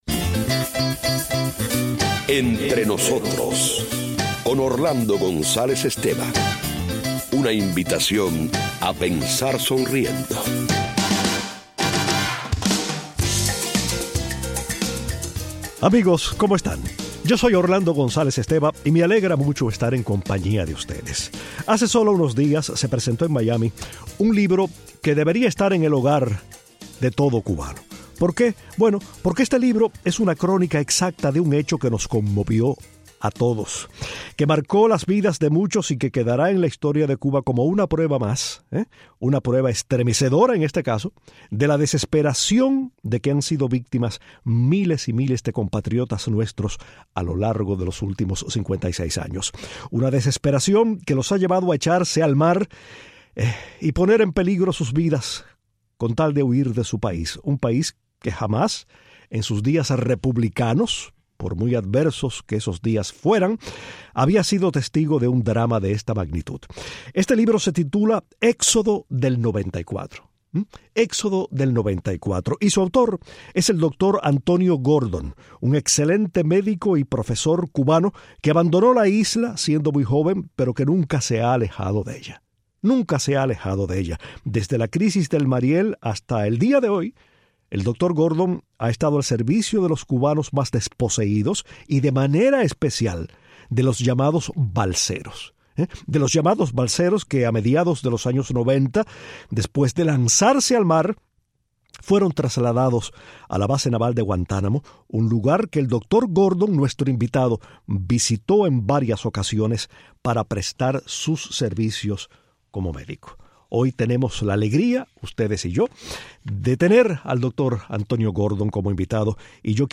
Hoy conversamos